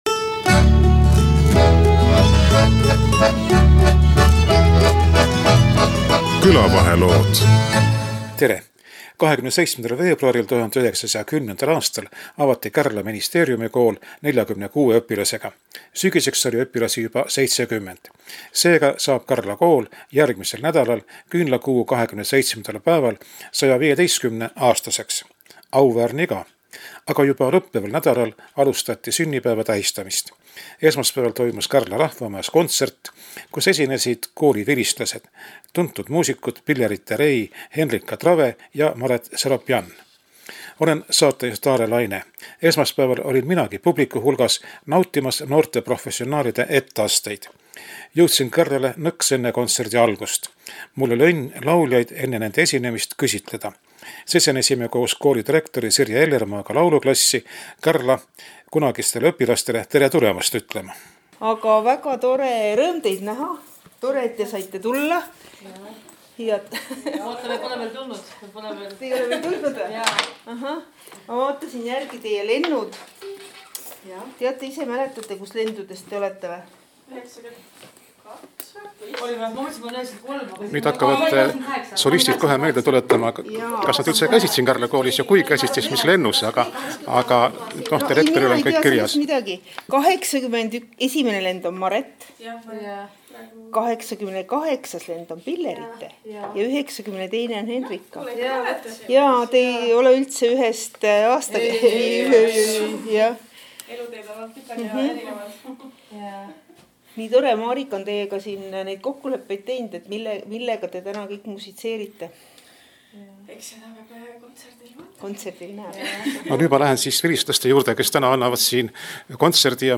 Saates kõlavad ka lood tuntud muusikute esituses.